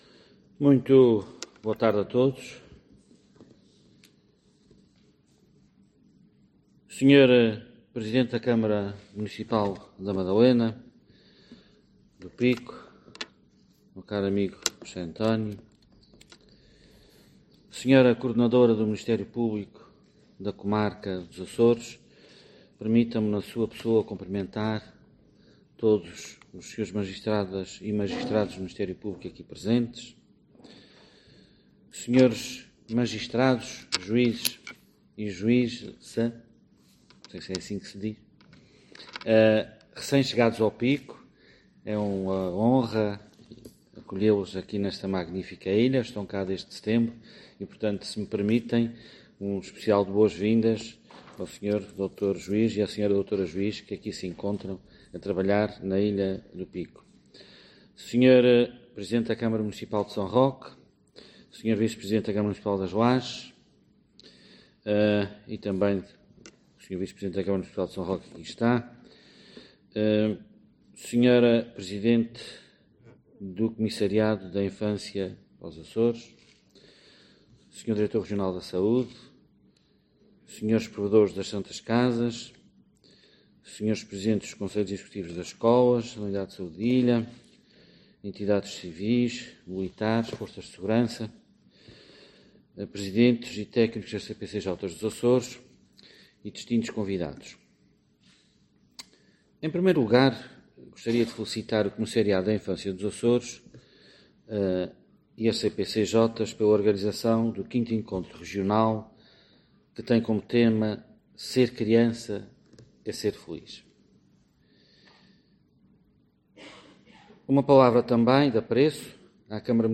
Artur Lima falava hoje na sessão de abertura do V Encontro Regional das CPCJ, organizado pelo Comissariado dos Açores para a Infância (CAI) e que está a decorrer no Auditório Municipal da Madalena do Pico.